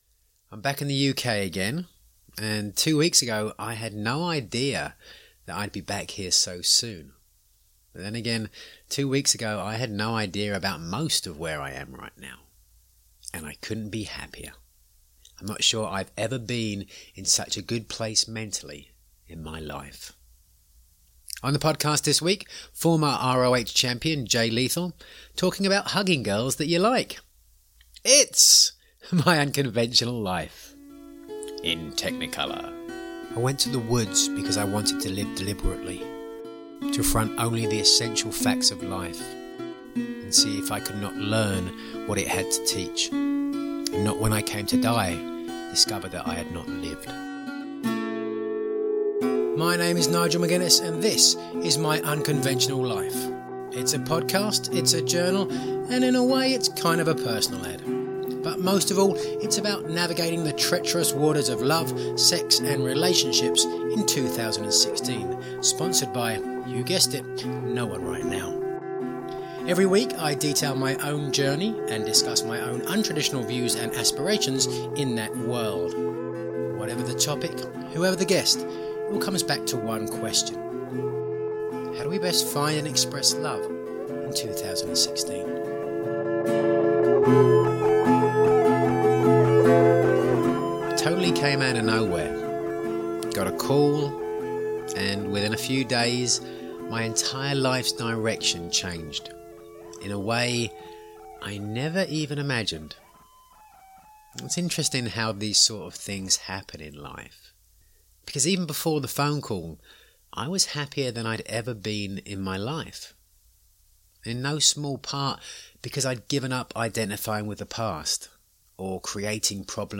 Monologue about a new job, a lovely message, and things picking up in my dating life. Plus part 1 of my interview with former Ring of Honor champion Jay Lethal talking about his traditional hopes for finding a partner.